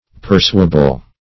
pursuable - definition of pursuable - synonyms, pronunciation, spelling from Free Dictionary
Search Result for " pursuable" : The Collaborative International Dictionary of English v.0.48: Pursuable \Pur*su"a*ble\, a. Capable of being, or fit to be, pursued, followed, or prosecuted.